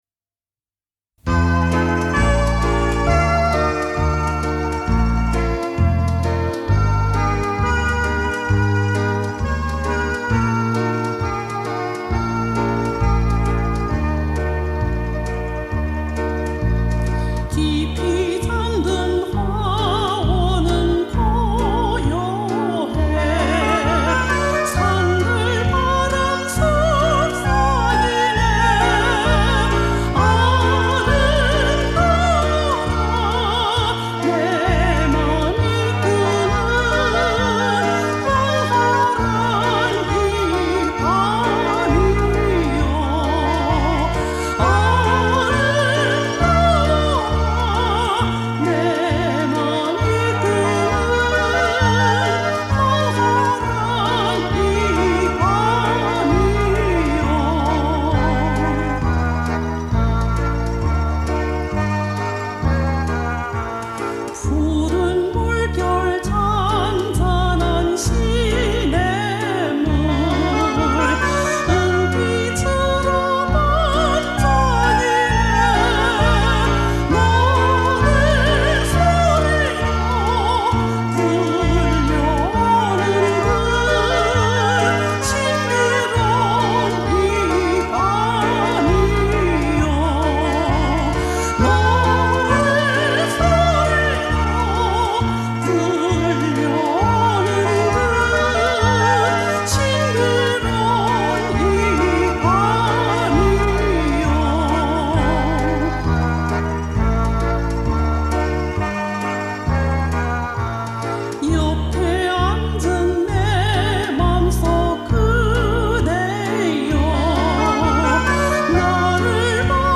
俄罗斯歌曲